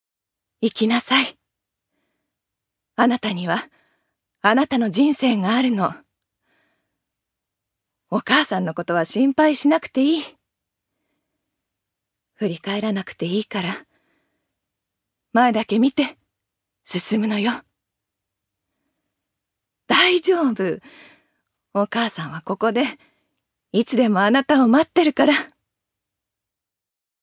ジュニア：女性
セリフ４